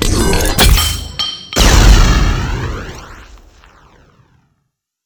plagrenade.wav